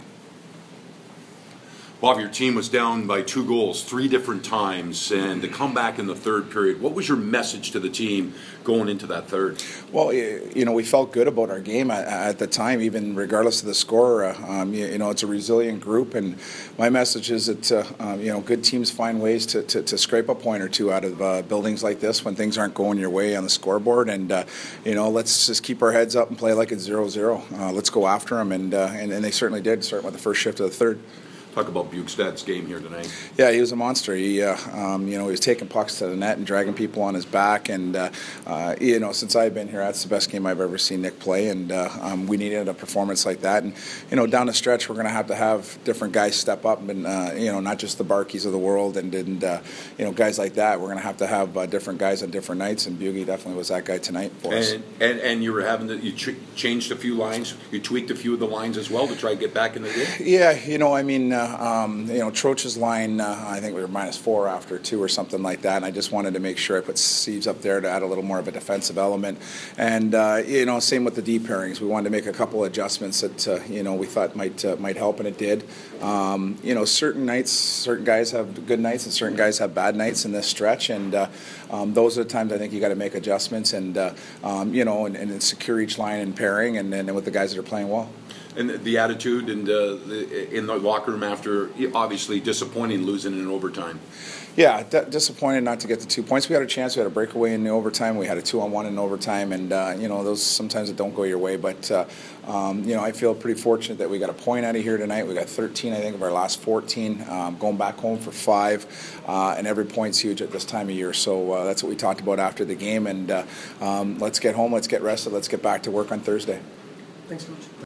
Panthers Head Coach Bob Boughner post-game 3/6